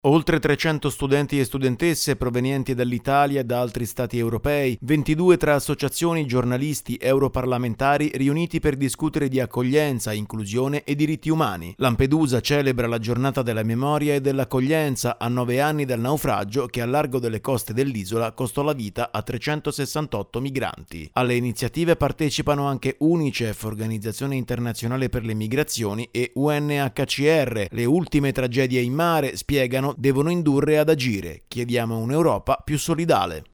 Oggi si celebra la giornata della memoria e dell’accoglienza per ricordare le persone morte in mare nel tentativo disperato di trovare sicurezza e protezione in Europa. Il servizio